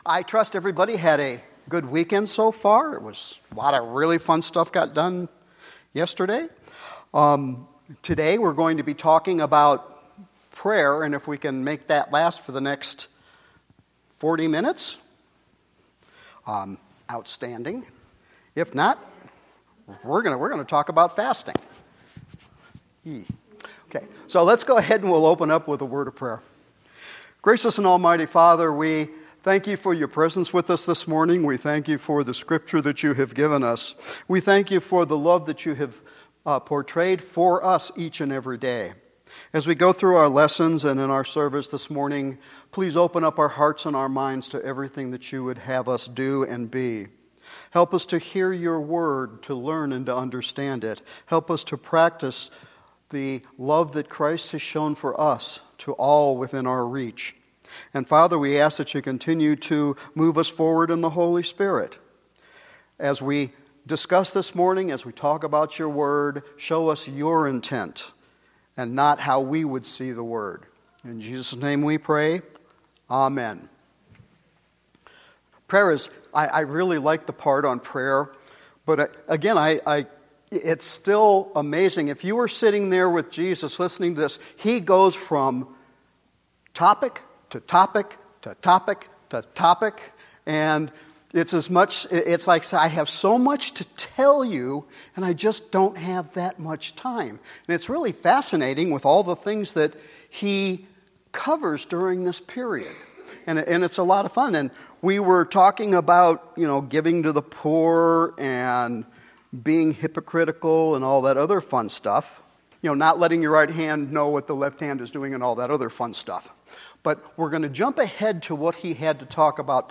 Be a Disciple About About Our Pastor Ministries Events Sermons & Bible Studies Give Contact The Sermon on the Mount - Charity and Prayer October 16, 2022